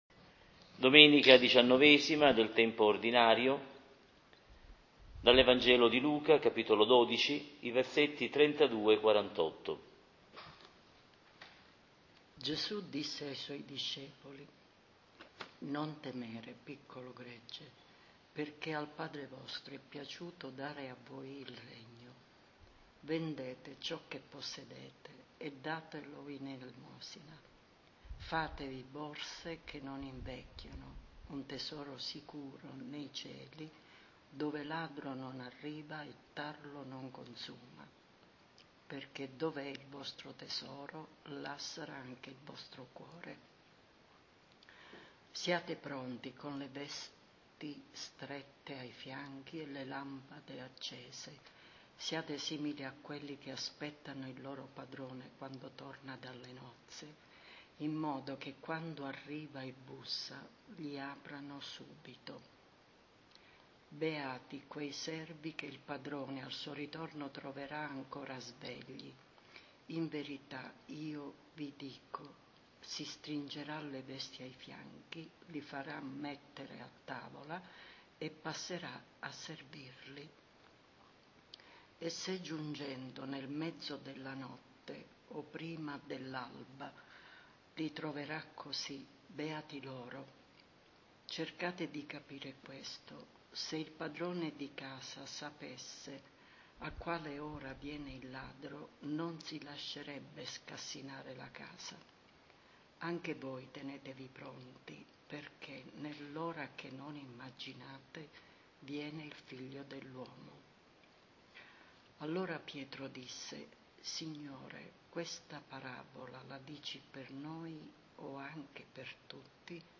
Lectio-XIX-Dom.-Tempo-Ord.-C-2022.mp3